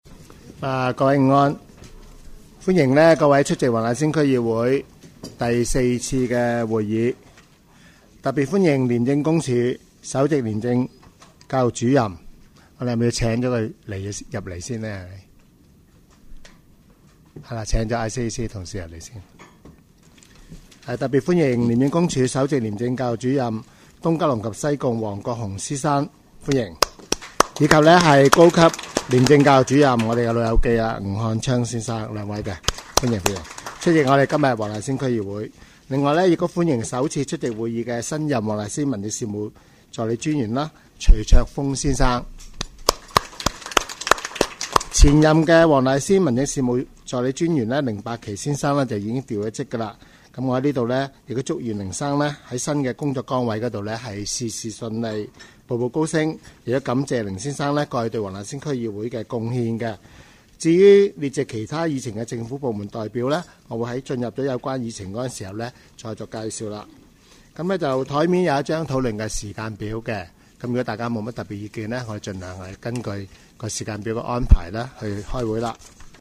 区议会大会的录音记录
黄大仙区议会会议室
主席致辞